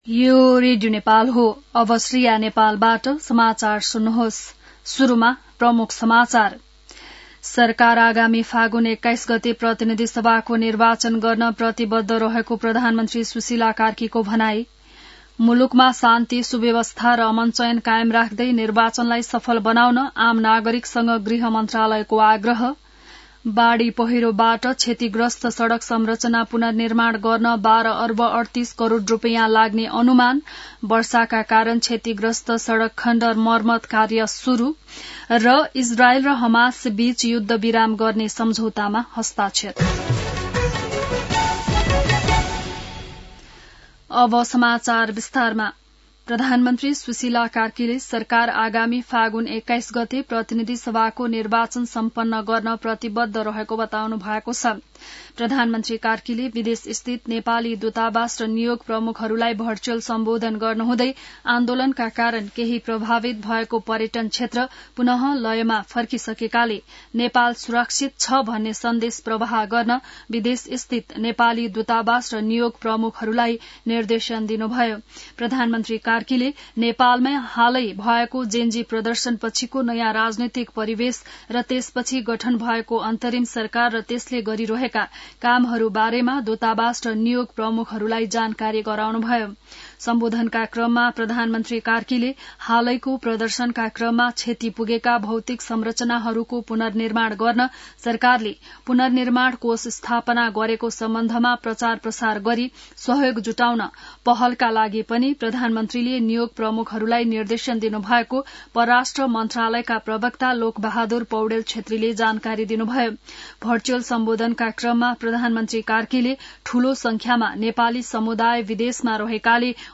बिहान ९ बजेको नेपाली समाचार : २३ असोज , २०८२